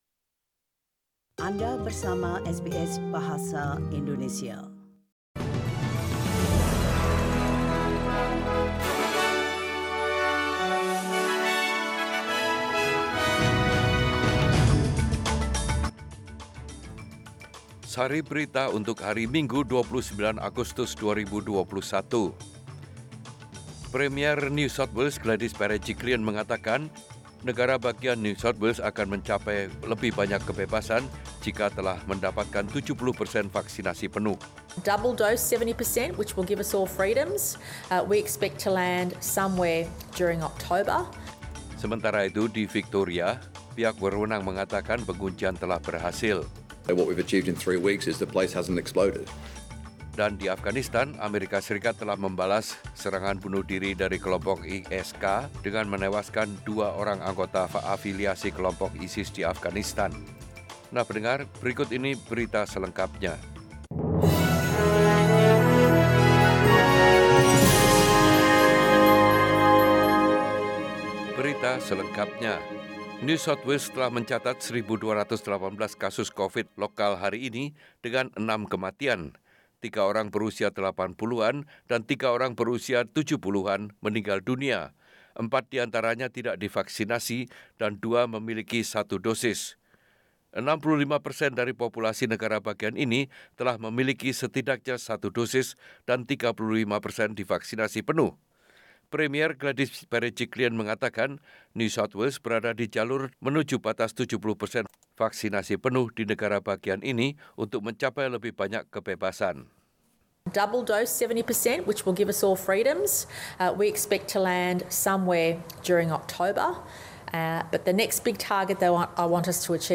SBS Radio News in Bahasa Indonesia - 29 August 2021
Warta Berita Radio SBS Program Bahasa Indonesia Source: SBS